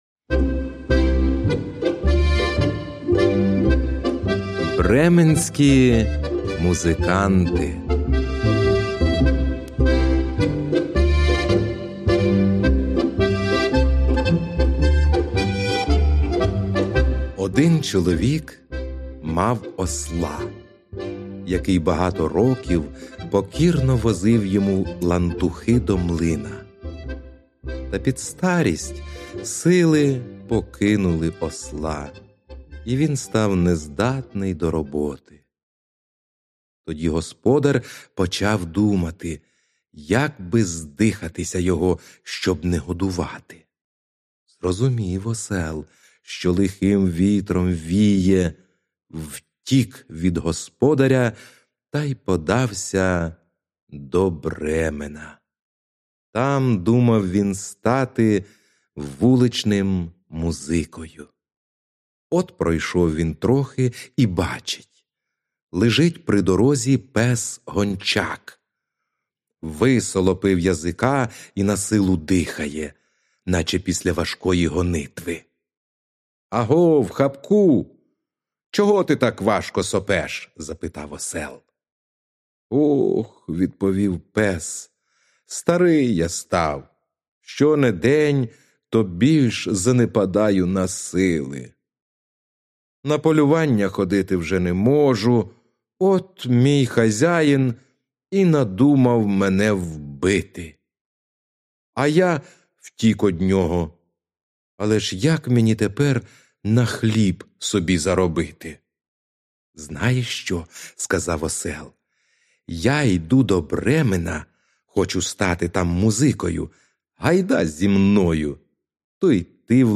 Слухати Аудіоказку Бременські музиканти українською мовою ⭐ Безкоштовно та без реєстрації.
Аудіоказка Бременські музиканти